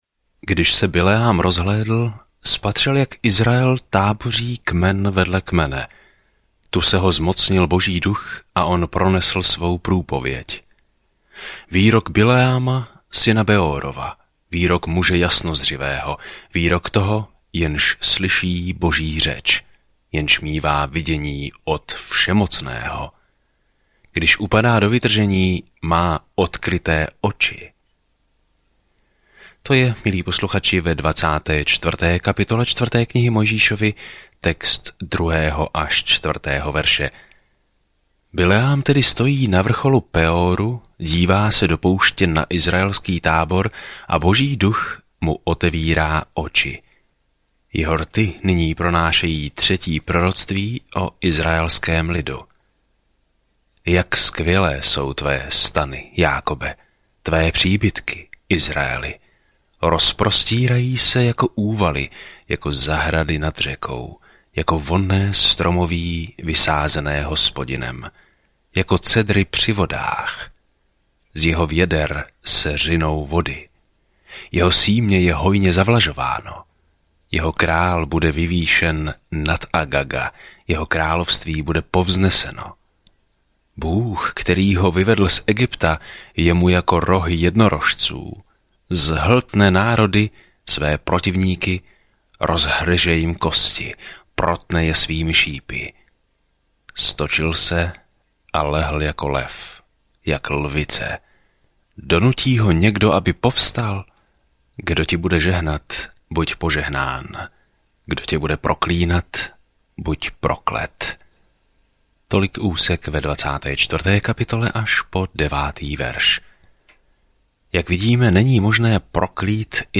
Písmo Numeri 24:2-25 Numeri 25 Numeri 26 Numeri 27:1 Den 14 Začít tento plán Den 16 O tomto plánu V knize Numeri chodíme, bloudíme a uctíváme s Izraelem 40 let v pustině. Denně procházejte Numbers, zatímco budete poslouchat audiostudii a číst vybrané verše z Božího slova.